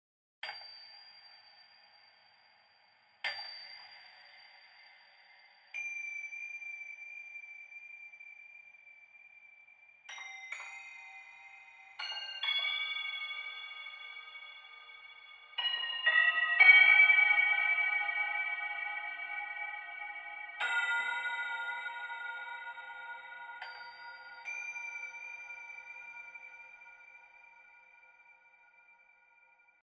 Die Nebengeräusche der Mechanik sind das Salz in der Suppe.
Sehr geheimnisvoll klingt die Underwater-Variante, bei der man sich in der Tat Aufnahmen aus der Tiefsee sehr gut vorstellen kann.
Das Instrument ist sehr leise. Eine Anhebung des Pegels gelingt jedoch ohne störendes Rauschen.